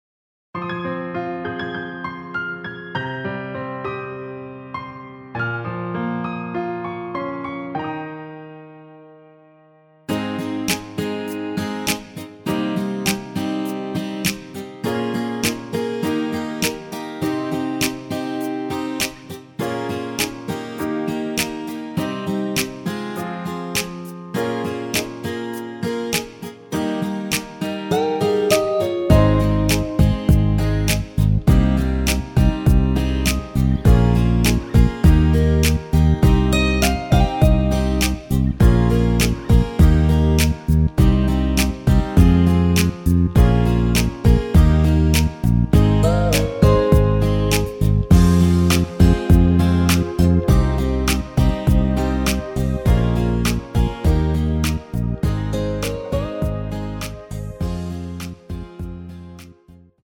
MR입니다. 원키에서(+5)올린 MR입니다.